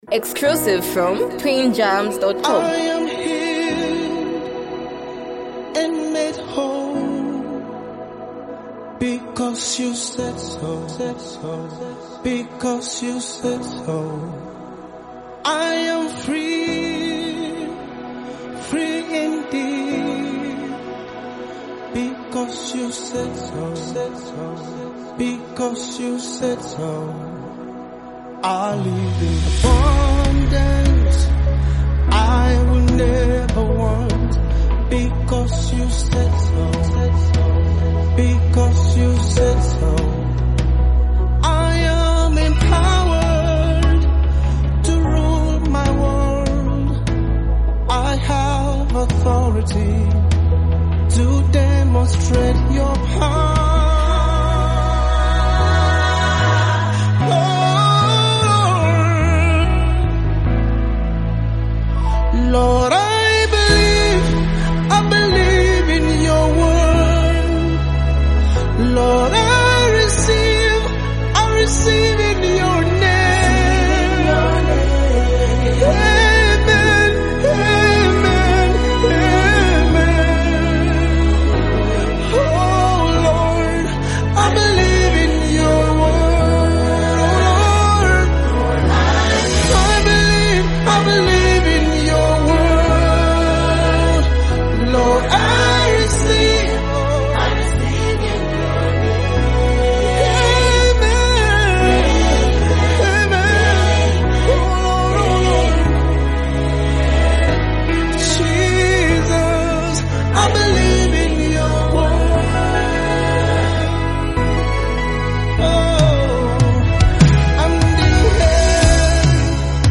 powerful worship song
Through sincere lyrics and inspiring melodies